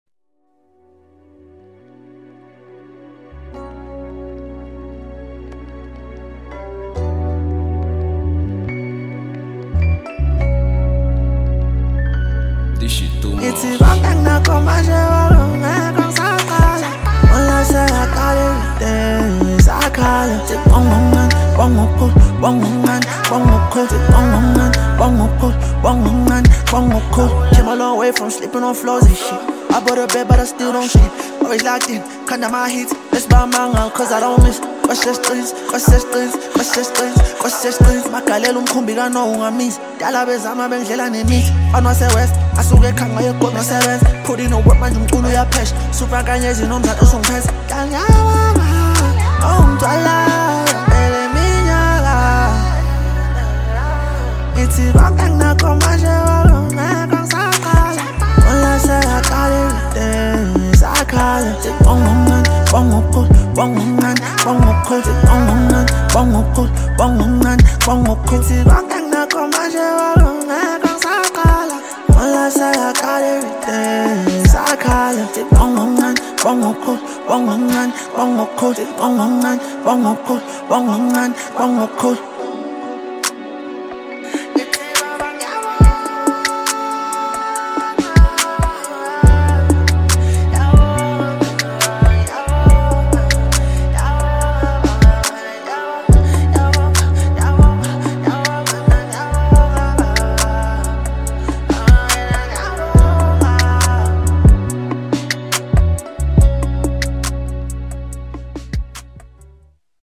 Hip Hop, DJ Mix, Lekompo